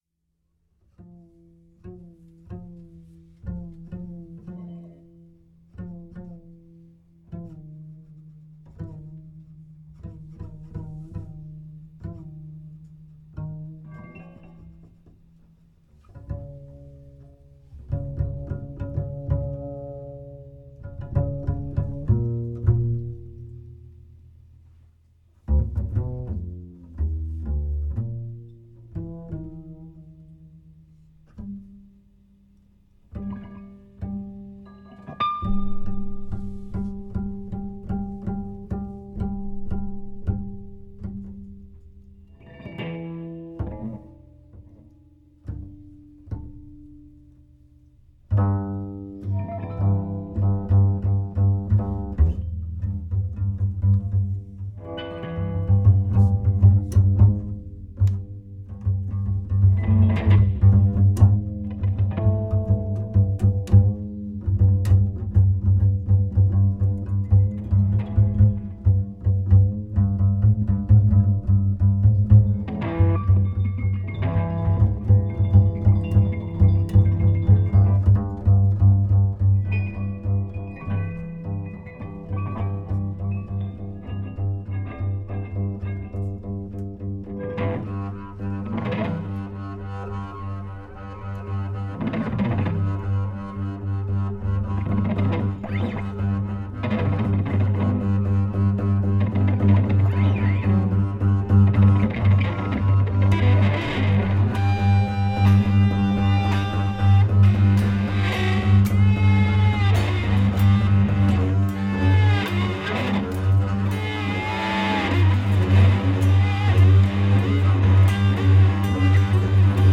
electric guitar
double bass
Recorded live
at "19PaulFort", Paris, France.